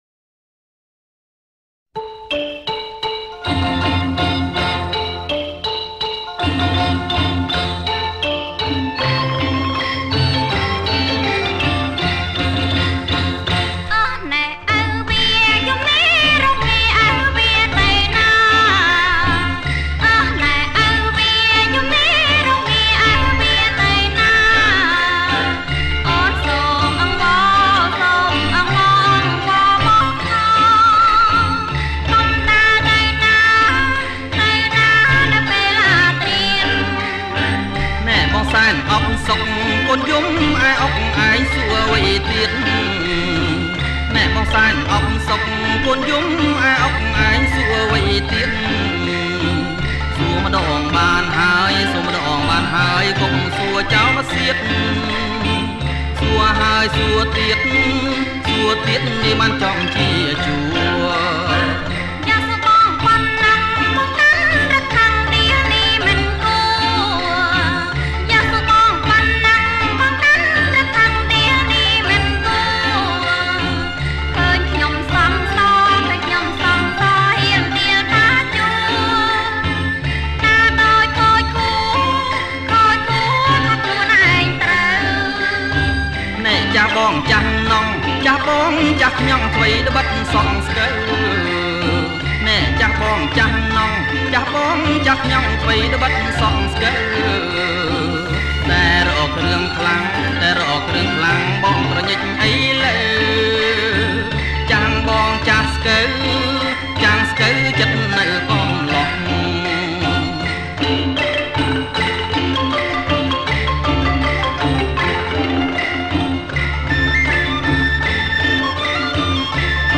• ចង្វាក់ រាំវង់